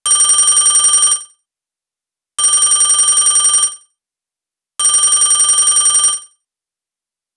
Vintage Telephone Ringtone - Bouton d'effet sonore